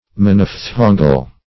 Search Result for " monophthongal" : The Collaborative International Dictionary of English v.0.48: Monophthongal \Mon`oph*thon"gal\, a. Consisting of, or pertaining to, a monophthong.